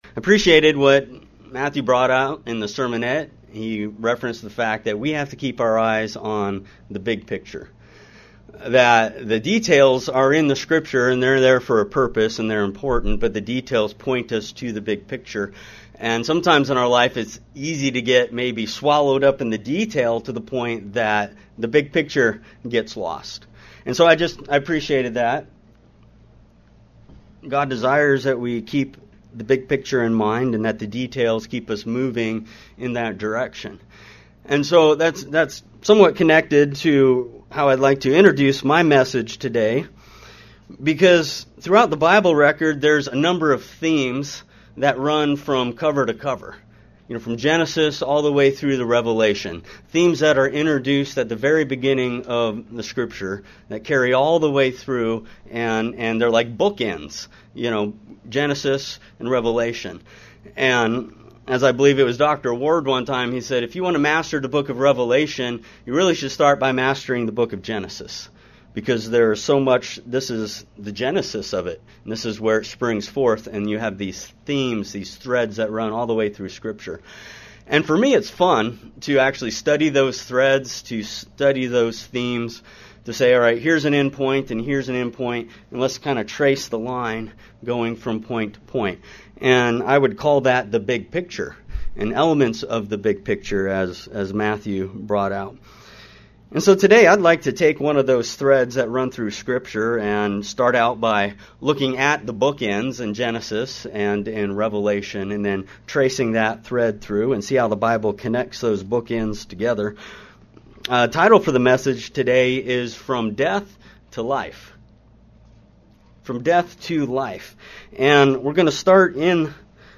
In this sermon he looks at the process of life and death as bookends framed by Genesis and Revelation.